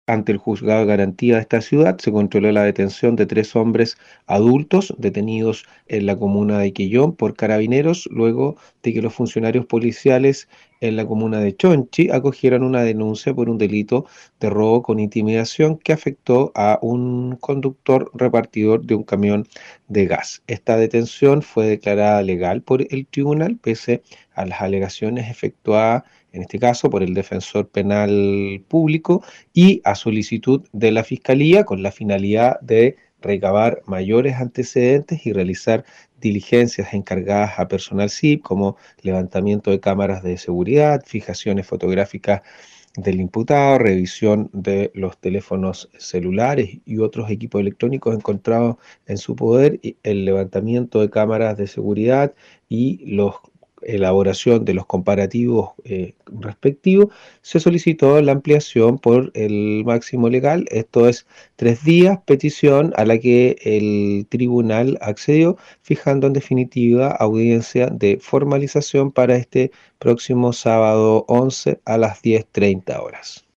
Respecto a la audiencia y las medidas cautelares adoptadas, se refirió el Fisca de Castro, Fernando Metzner: